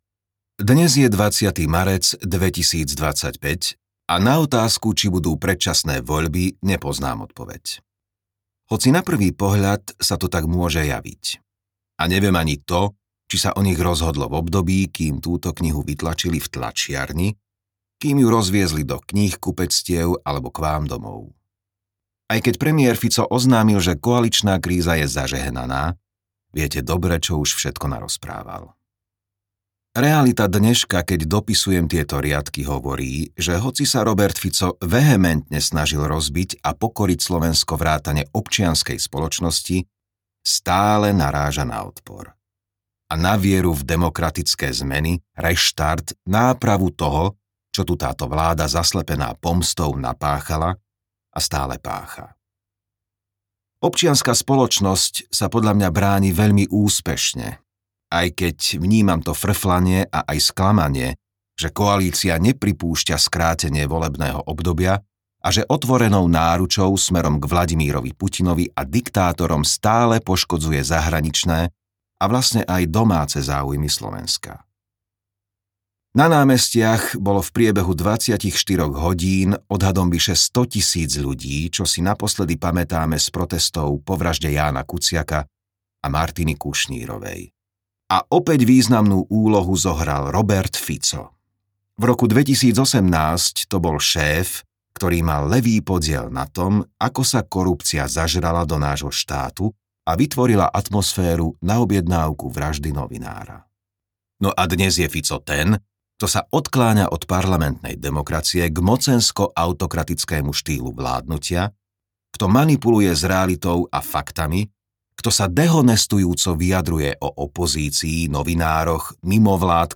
Fico: Posadnutý pomstou audiokniha
Ukázka z knihy